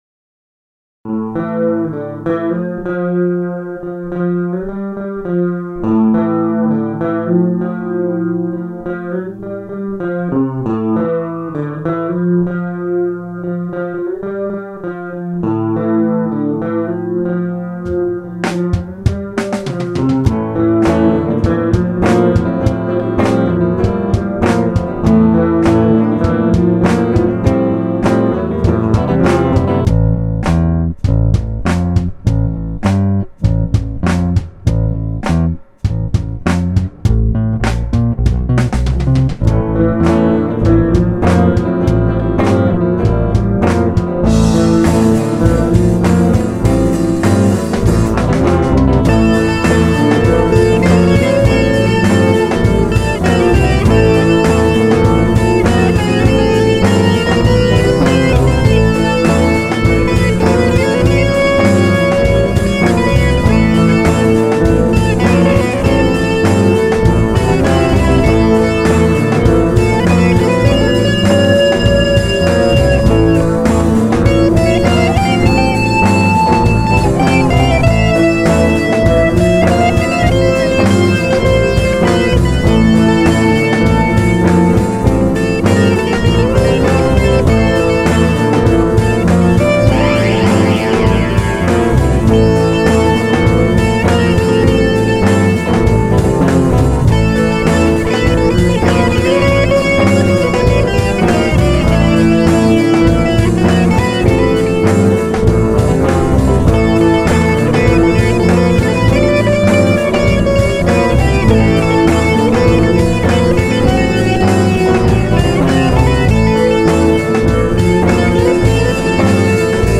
Acoustic & Electric Guitar, Bass Guitar, Trumpet, Vocals
Music Style / Genre Rock